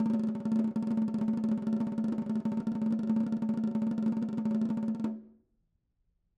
Snare2-rollNS_v3_rr1_Sum.wav